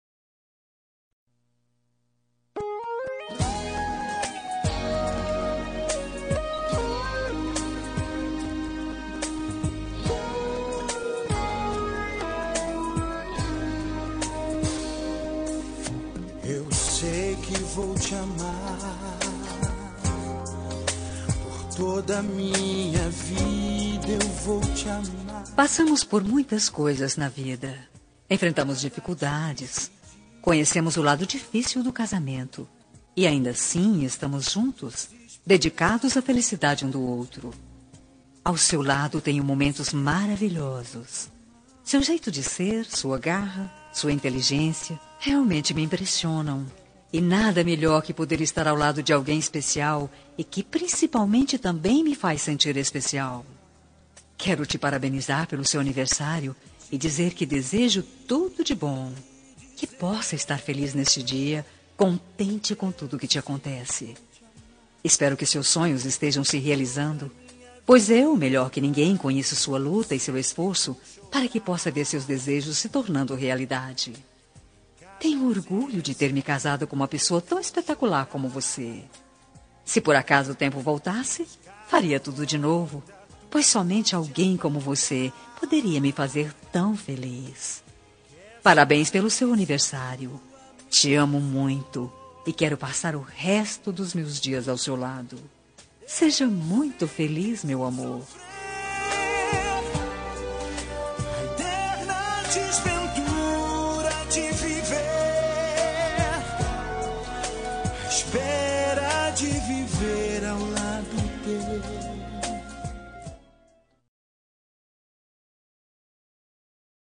Telemensagem de Aniversário de Marido – Voz Feminina – Cód: 1151